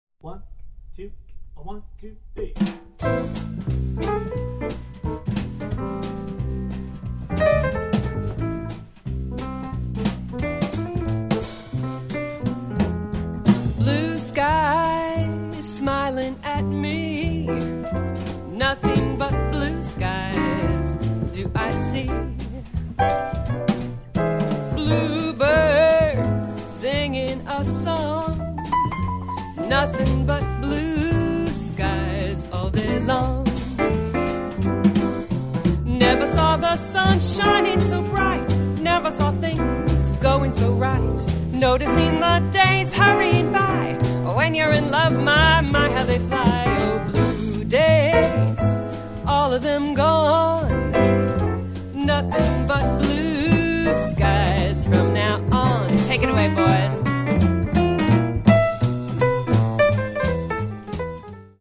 A vibrant vocalist with a love of both jazz and modern music
rich and expressive vocals
jazz vocalist